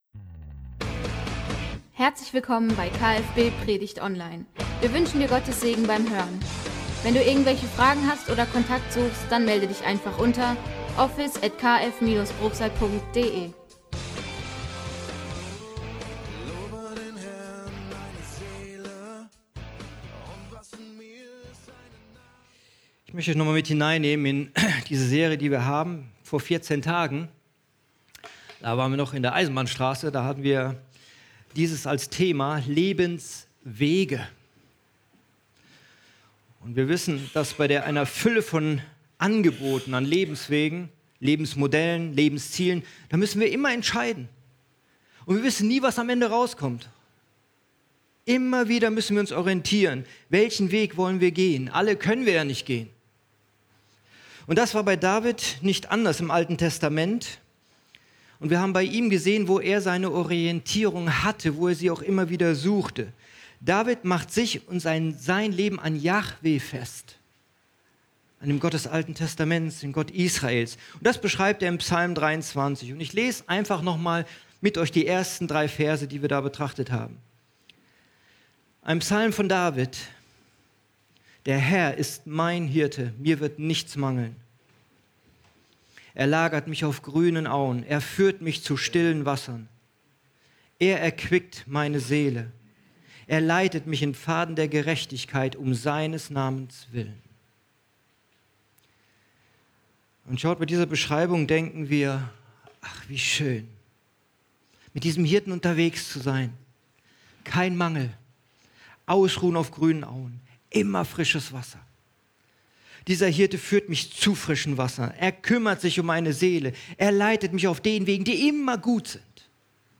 Wir bitten daher noch um etwas Geduld, bis die Tonqualität der Predigt wieder auf gewohntem Niveau liegt…